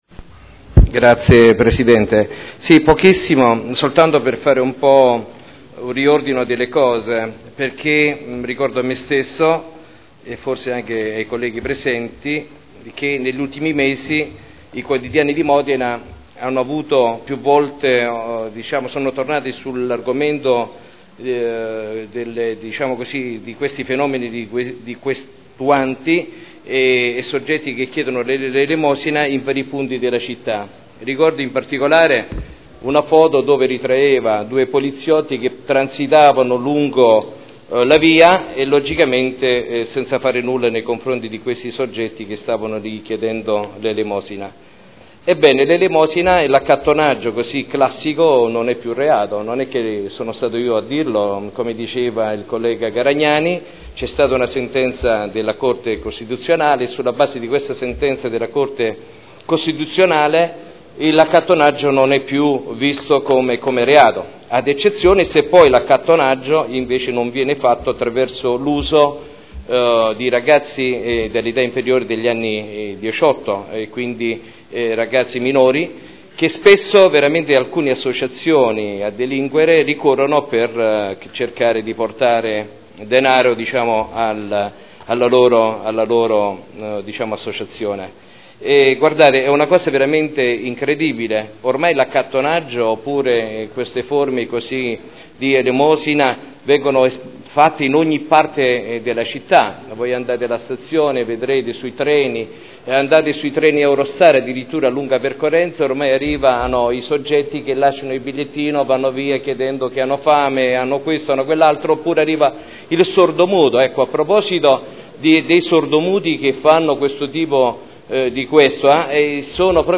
Seduta del 20/06/2011. Modifiche al Regolamento di Polizia Urbana approvato con deliberazione del Consiglio comunale n. 13 dell’11.2.2002 Dibattito